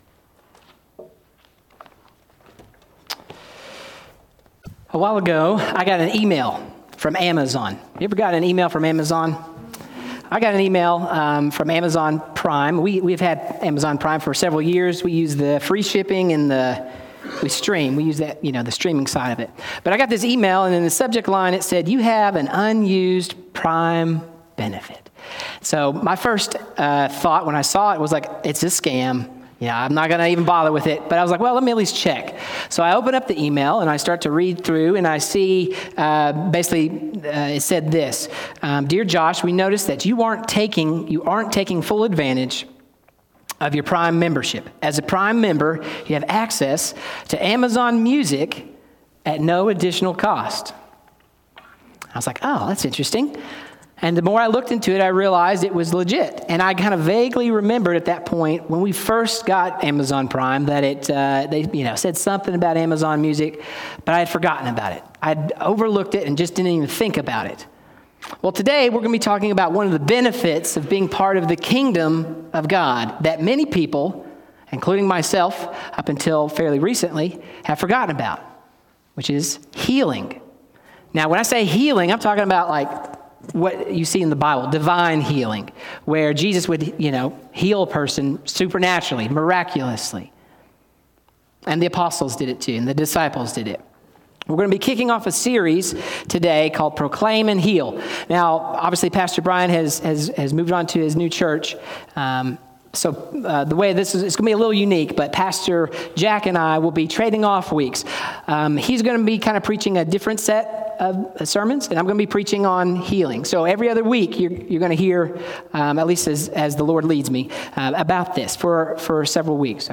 Sermons | Robertsville Baptist Church